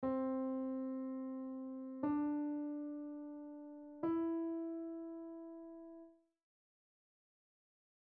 Piano Notes
cde.mp3